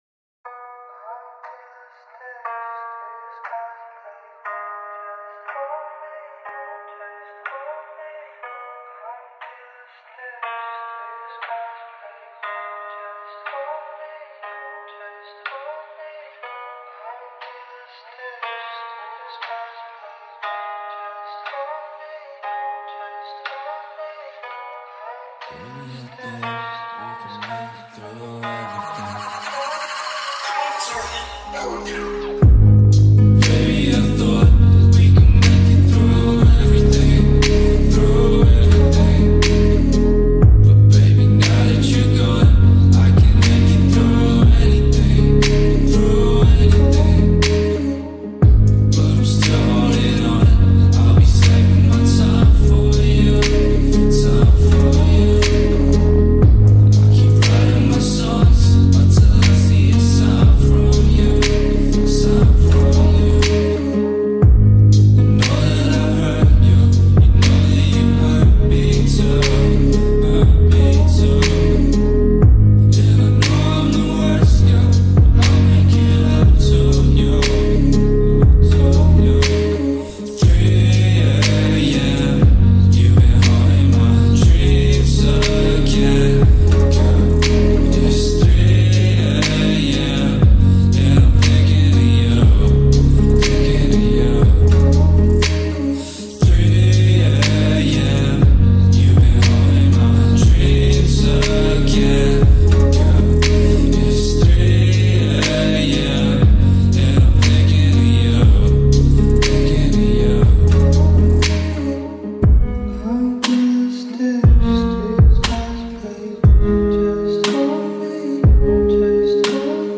ورژن 8 بعدی